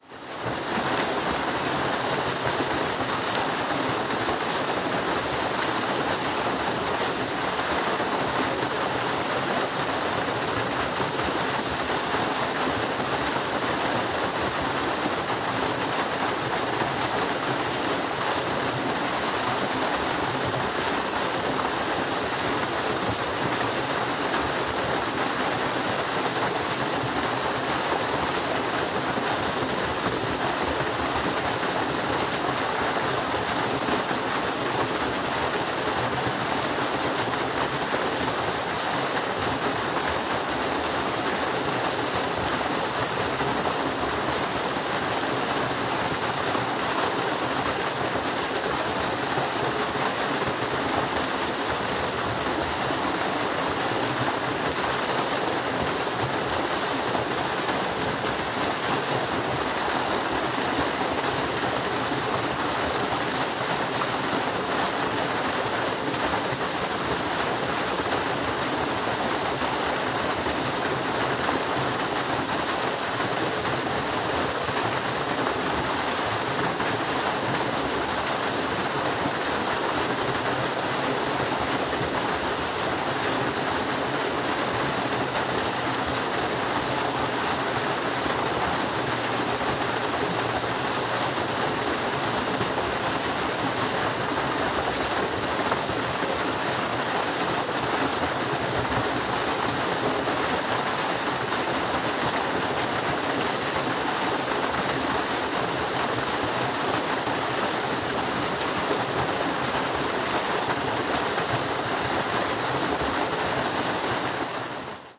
现场记录。水 " 森林中的大瀑布
描述：使用内置麦克风的Zoom H1录制的Wav文件。在森林里的一个大瀑布。在提契诺（Tessin），瑞士。
Tag: 瑞士 fieldrecording 流量 流域 瀑布 和平 自然 放松 fieldrecording 落下 环境 流动 森林 下降 提契诺 汩汩 环境 森林 水流 鼓泡 流溪 提契诺